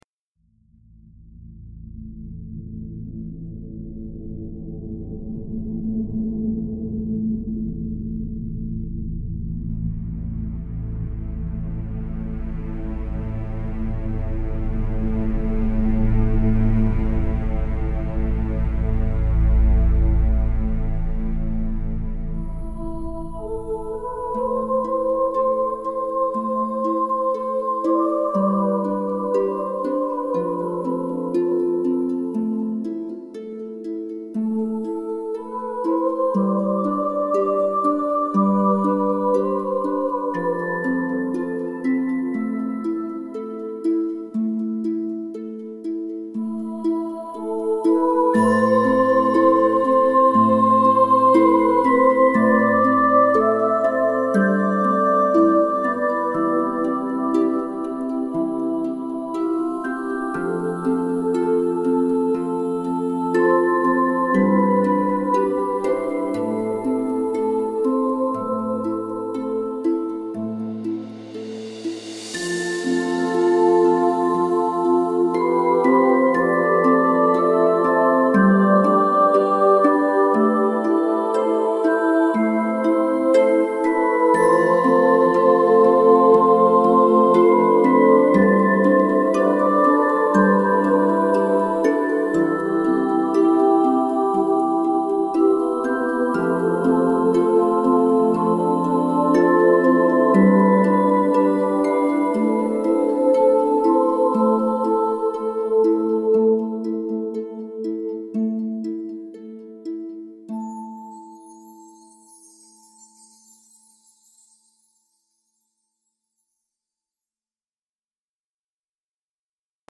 mystical background with fantasy vibe.